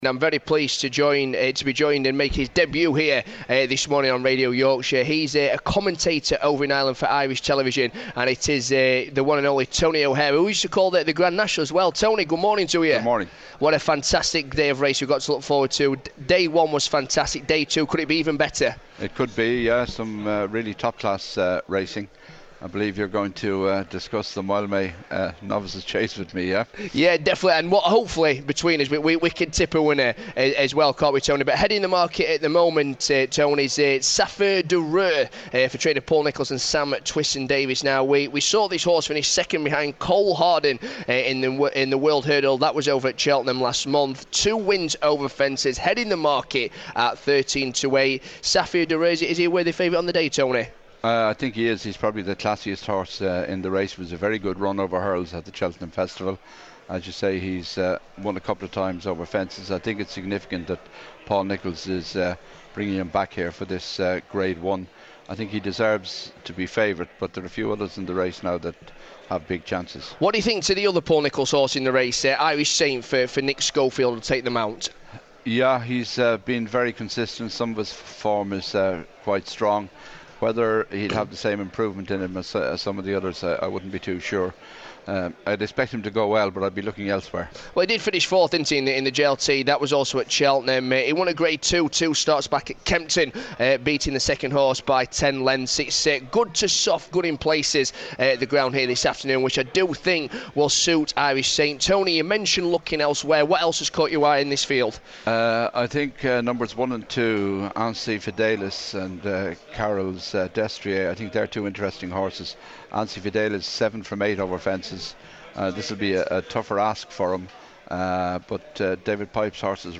is at Aintree for the 2nd day of racing at the Grand National Festival.